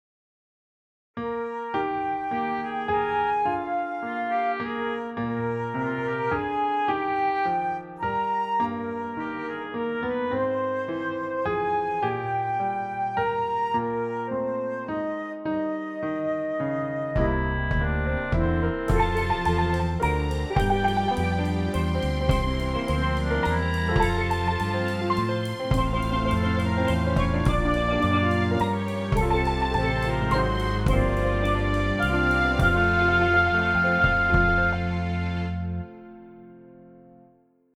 Hymns Playing